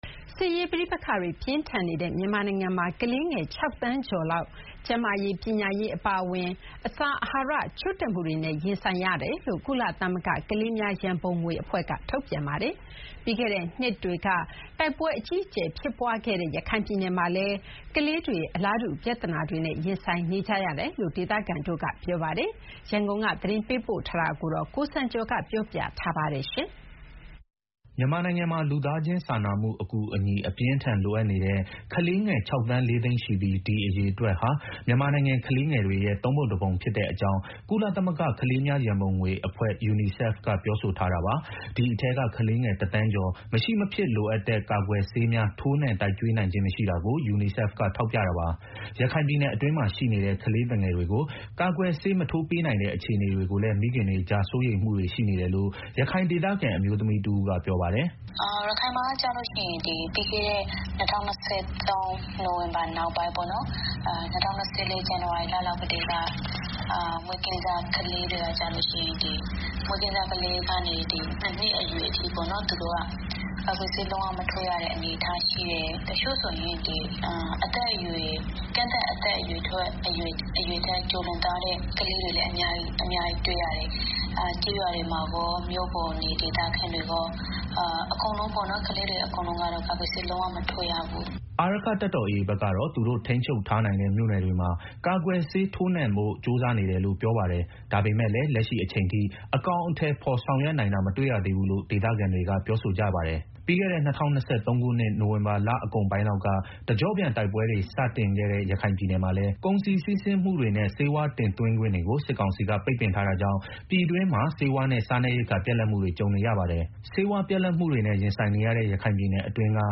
စစ်ရေးပဋိပက္ခတွေ ပြင်းထန်နေတဲ့ မြန်မာနိုင်ငံမှာ ကလေးငယ် ၆ သန်းကျော်လောက်ဟာ ကျန်းမာရေး ပညာရေးအပါအဝင် အစာအာဟာရ ချို့တဲ့မှုတွေနဲ့ ရင်ဆိုင်နေရတယ်လို့ ကုလသမဂ္ဂကလေးများရန်ပုံငွေအဖွဲ့ (UNICEF) က ထုတ်ပြန်ထားပါတယ်။ ပြီးခဲ့တဲ့နှစ်တွေက တိုက်ပွဲအကြီး အကျယ်ဖြစ်ပွားခဲ့တဲ့ ရခိုင်ပြည်နယ်မှာလည်း ကလေးတွေ အလားတူပြဿနာတွေနဲ့ ရင်ဆိုင်နေကြရတယ်လို့ ဒေသခံတွေက ပြောပါတယ်။ ဒီအကြောင်းအပြည့်အစုံကို ရန်ကုန်က သတင်းပေးပို့ထားပါတယ်။
အခုလိုအခြေအနေတွေကြောင့် ရခိုင်ပြည်နယ်အတွင်းမှာရှိနေတဲ့ ကလေးသူငယ်တွေကို ကာကွယ်ဆေး ထိုးမပေးနိုင်တဲ့အတွက် မိခင်တွေကြား စိုးရိမ်မှုတွေရှိနေတယ်လို့ ရခိုင်ဒေသခံအမျိုးသမီးတဦးက ပြောပါတယ်။